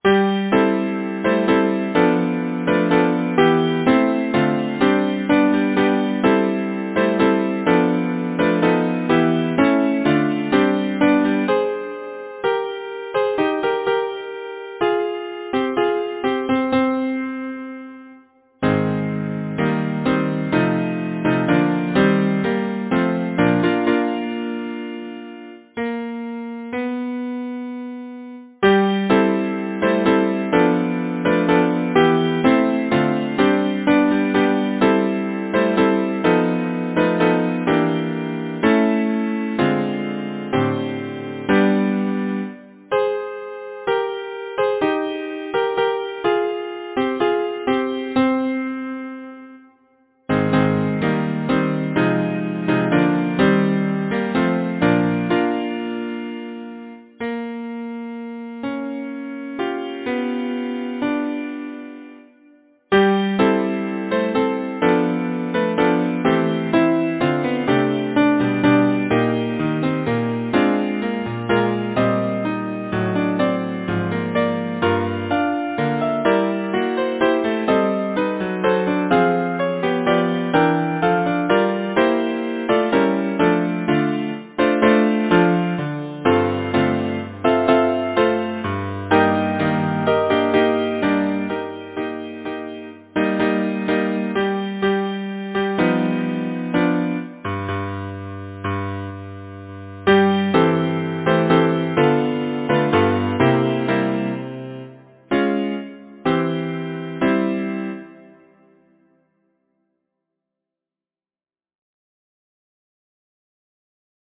Title: Proud Maisie Composer: John Pointer Lyricist: Walter Scott Number of voices: 4vv Voicing: SATB, divisi Genre: Secular, Partsong
Language: English Instruments: A cappella